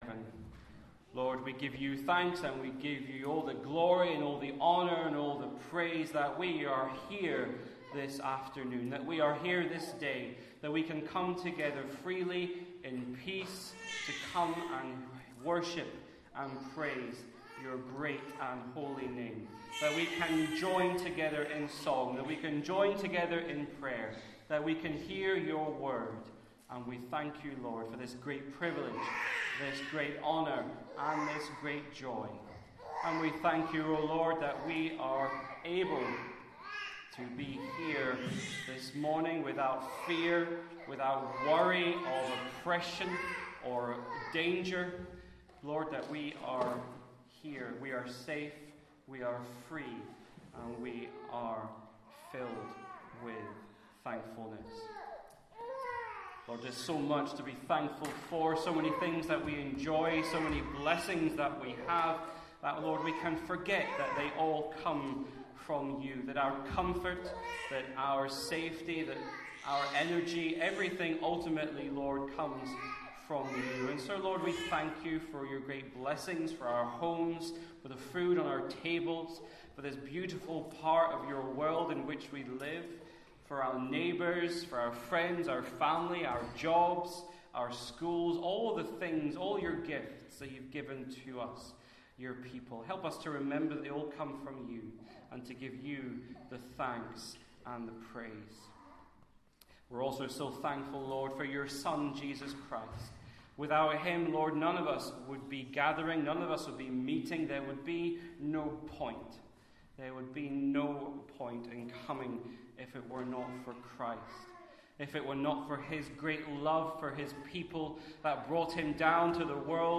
Sunday Service 12 Noon